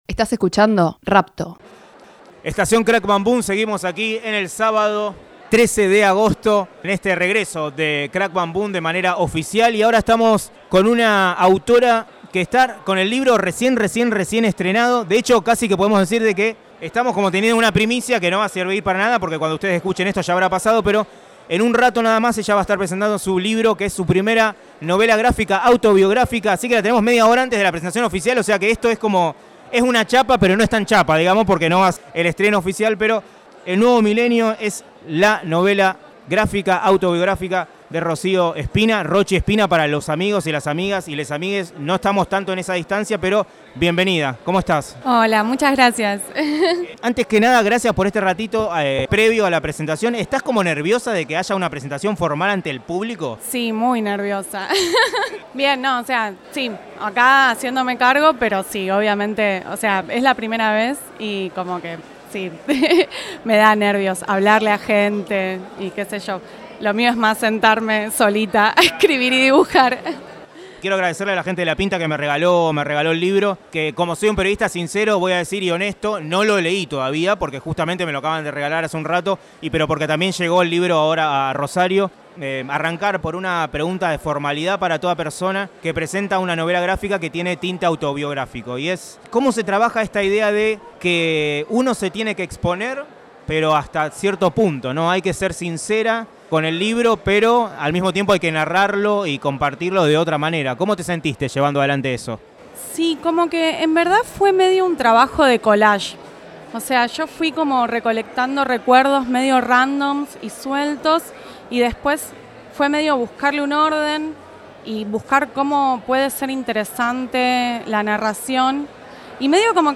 La serie se grabó en el subsuelo del Galpón 11, en la comodidad del living de Espacio Moebius.